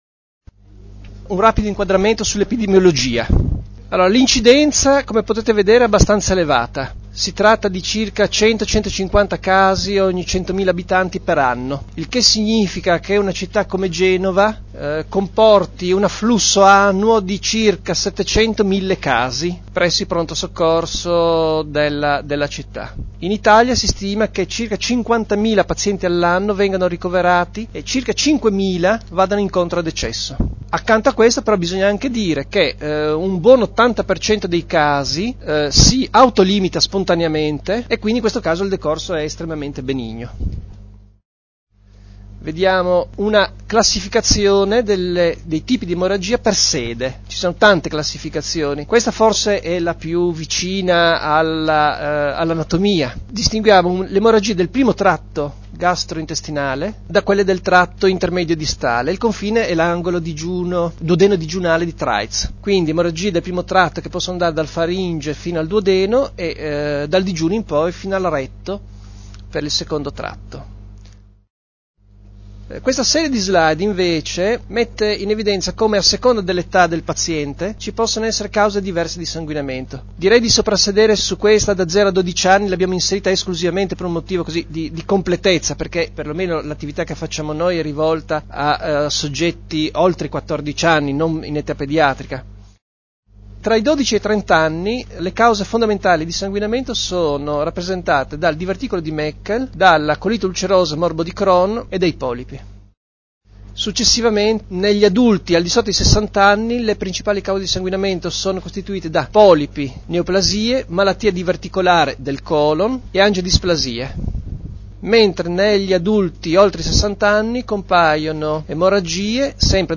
registrazione dell'audio della lezione dal vivo
lezione_emorragie.mp3